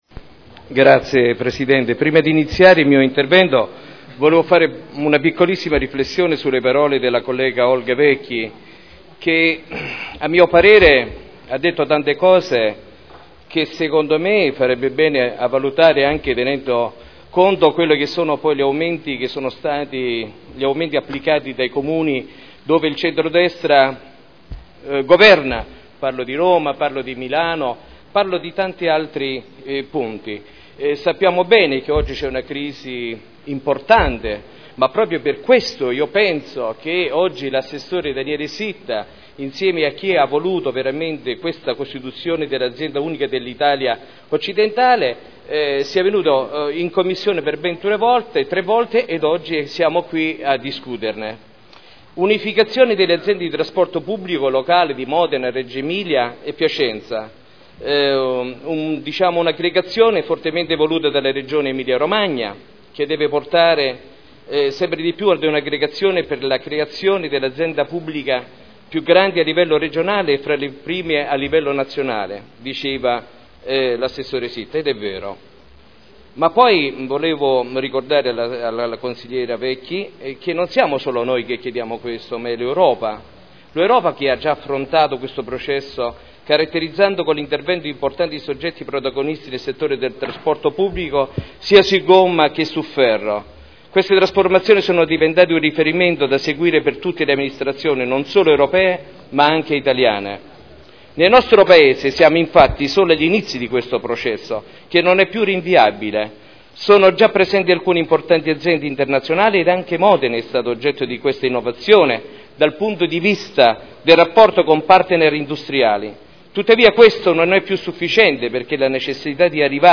Seduta del 3 ottobre 2011 Proposta di deliberazione: Aggregazione dei soggetti affidatari del Servizio di Trasporto Pubblico Locale nei bacini di Modena, Reggio Emilia e Piacenza Dibattito su proposta di delibera e odg presentati in corso del Consiglio Comunale